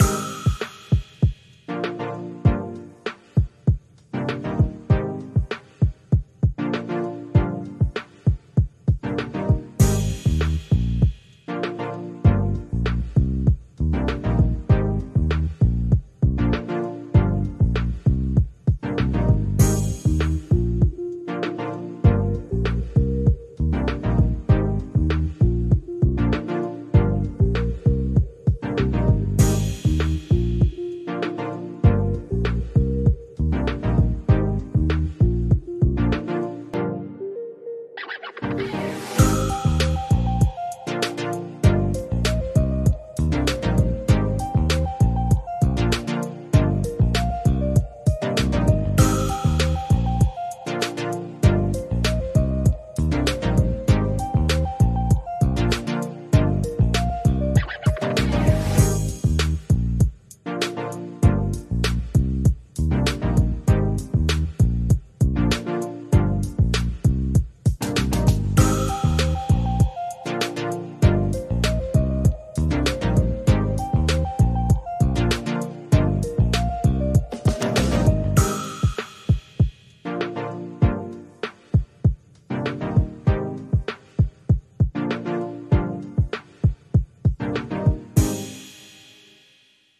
音楽プレイヤーから流れるリズミカルな音楽が空気を揺らし、そのビートに合わせて一生懸命ステップを踏む。